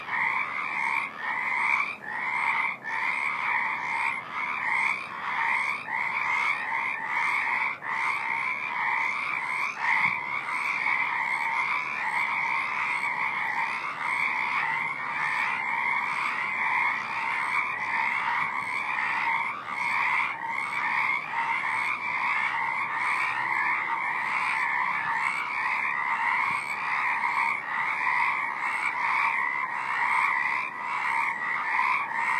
Pacific tree frog
Visiting frog
new-recording-2frogs.m4a